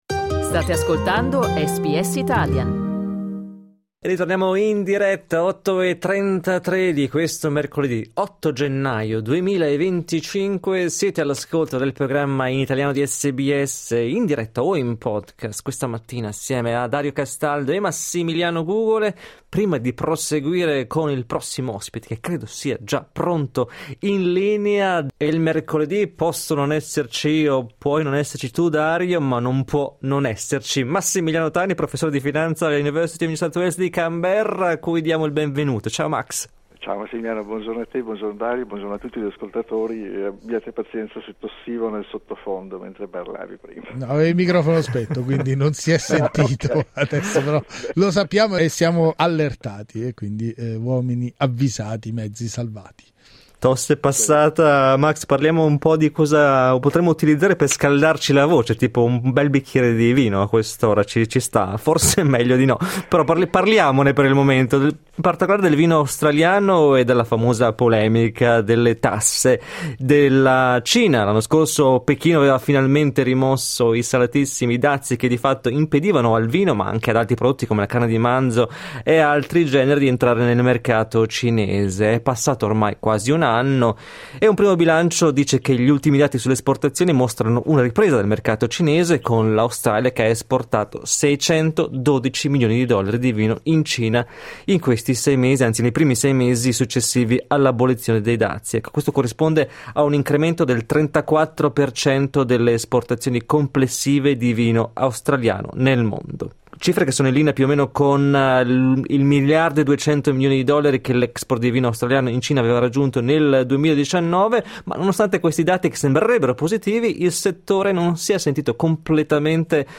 Clicca il tasto 'play' in alto per ascoltare l'analisi del professore di finanza